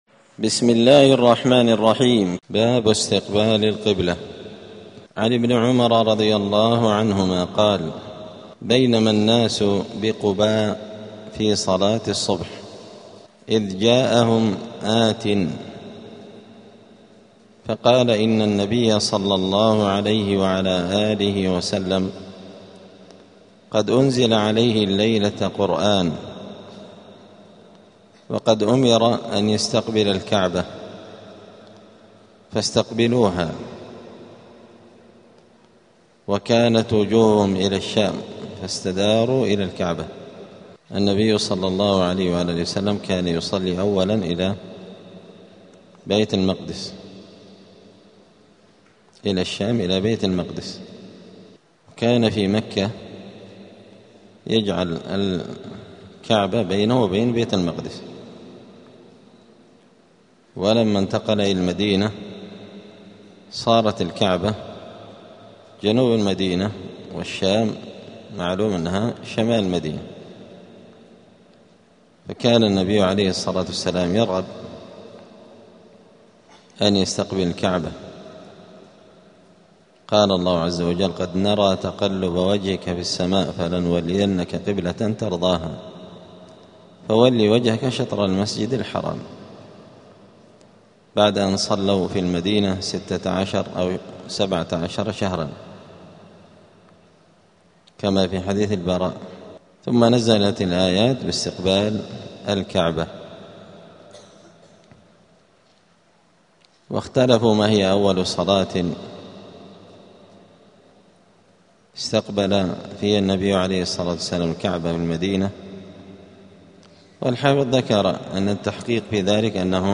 دار الحديث السلفية بمسجد الفرقان قشن المهرة اليمن
*الدرس الثاني والسبعون بعد المائة [172] باب استقبال القبلة {وجوب استقبال القبلة وهو شرط لصحة الصلاة}*